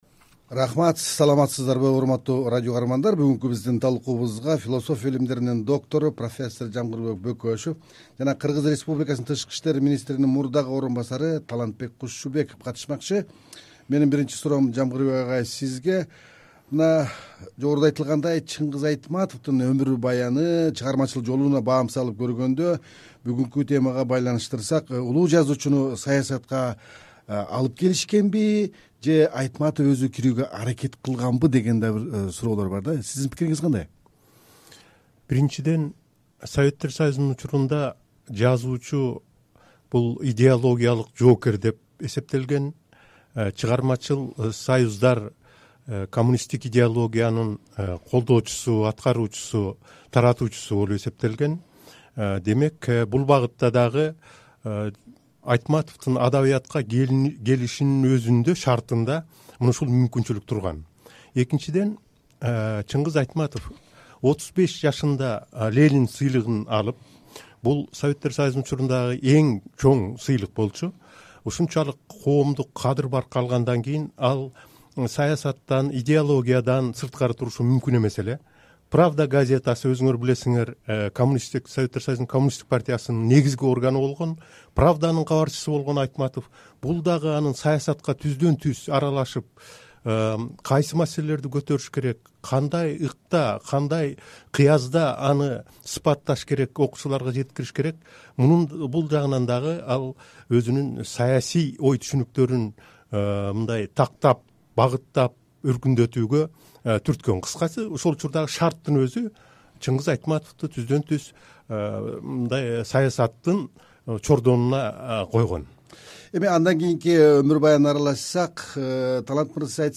Талкуу: Айтматовду саясатка коомдук кырдаал алып келген